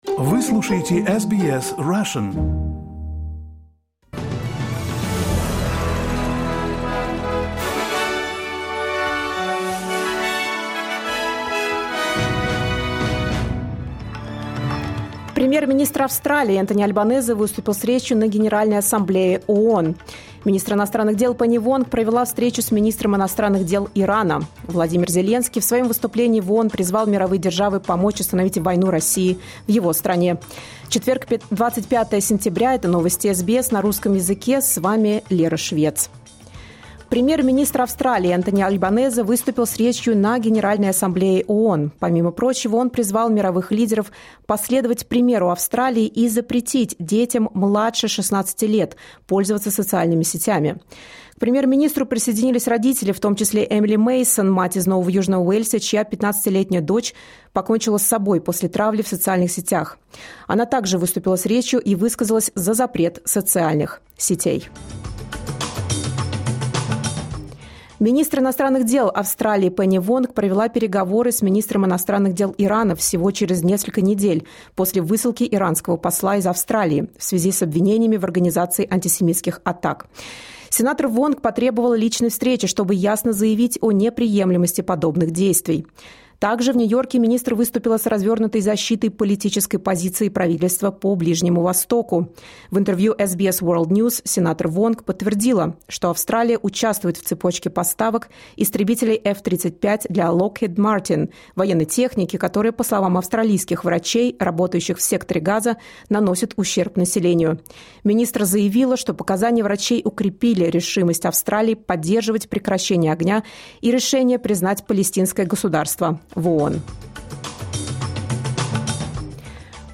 Новости SBS на русском языке — 25.09.2025